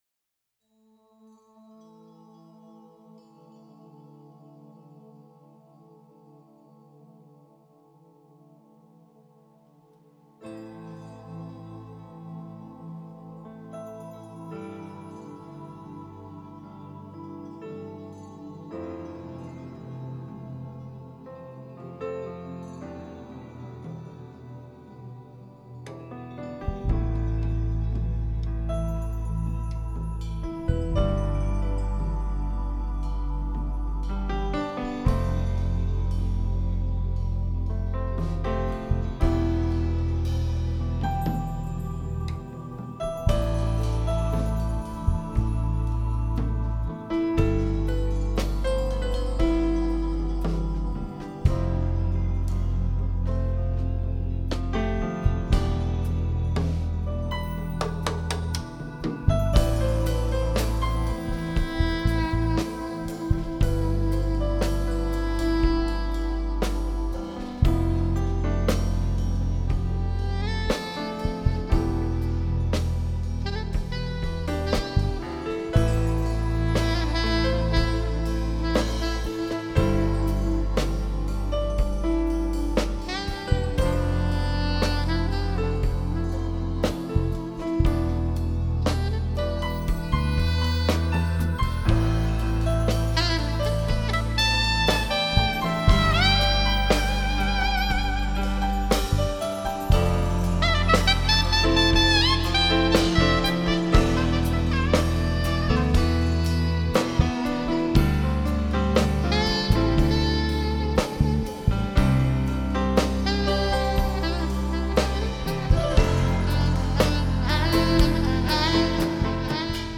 Experimental instrumental music
sax, keyboards